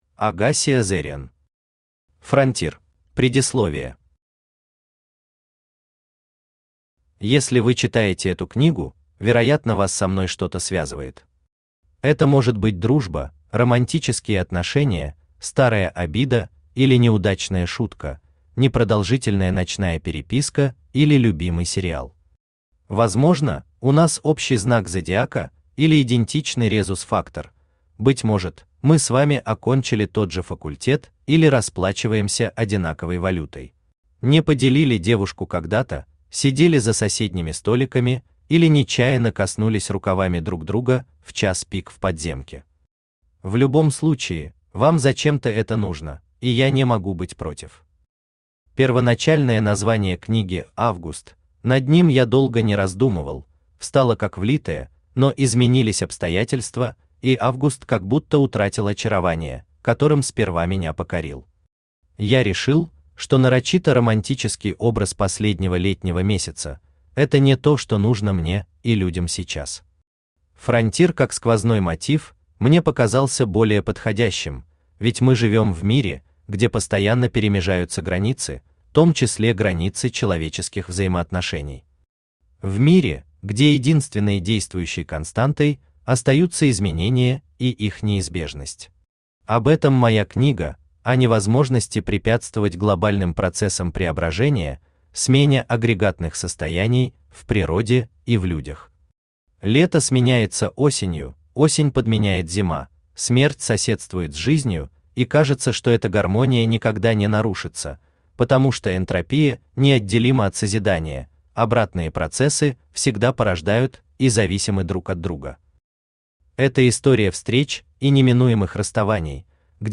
Aудиокнига Frontier Автор Agasi Azarian Читает аудиокнигу Авточтец ЛитРес.